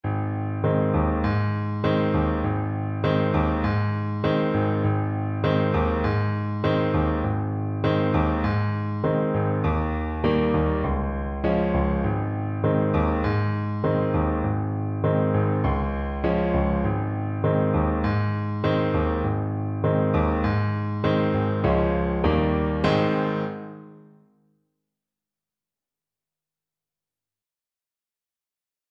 Trumpet
Ab major (Sounding Pitch) Bb major (Trumpet in Bb) (View more Ab major Music for Trumpet )
Moderato
4/4 (View more 4/4 Music)
Bb4-Ab5
Traditional (View more Traditional Trumpet Music)
world (View more world Trumpet Music)
Congolese